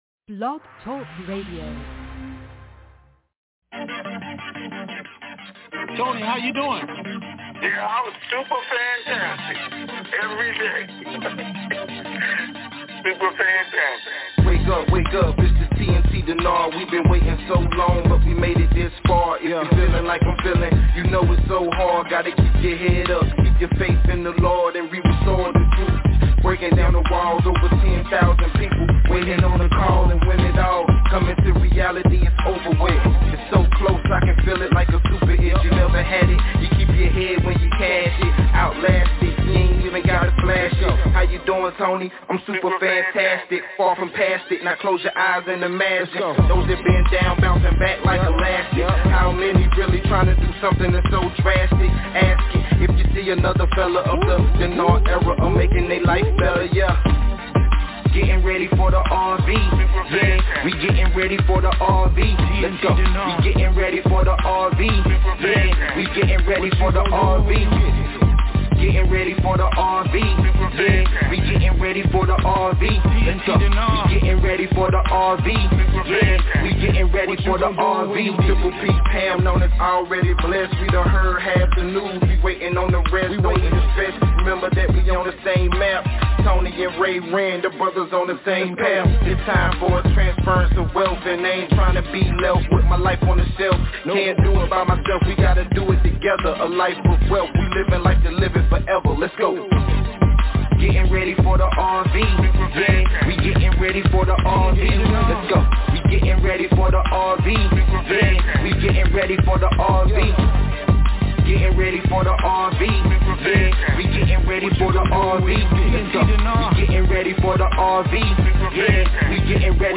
TNTSuperfantastic Conference Call - Friday, December 1, 2023